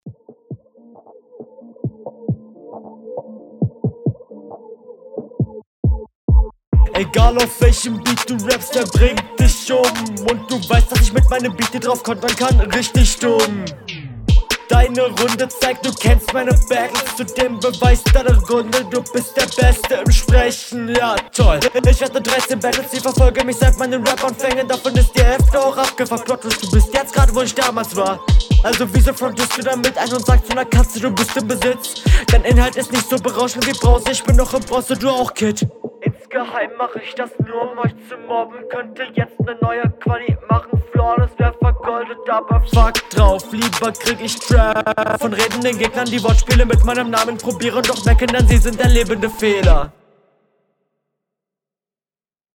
Rückrunde 2
Auf dem Beat kommt du etwas schlechter als in Runde 1.